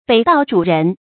北道主人 注音： ㄅㄟˇ ㄉㄠˋ ㄓㄨˇ ㄖㄣˊ 讀音讀法： 意思解釋： 北道上接待過客的主人。